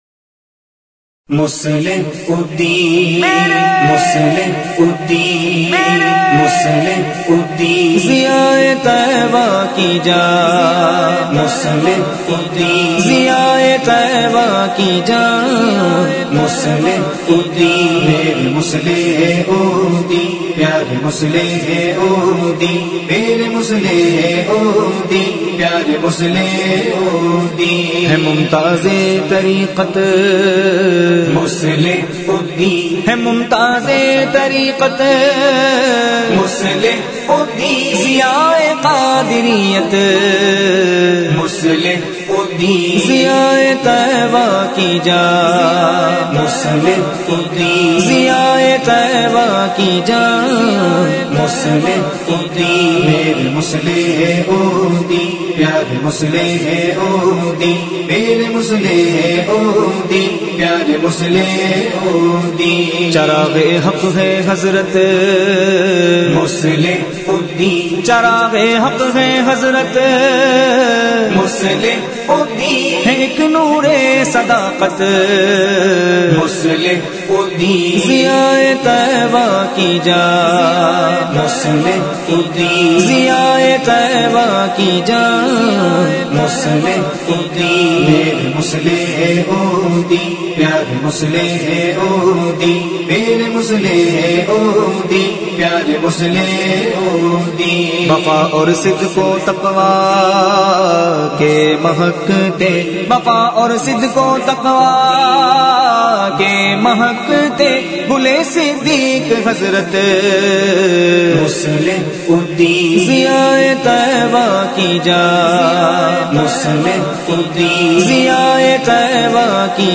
منقبت